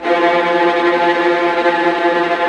VIOLINT GN-L.wav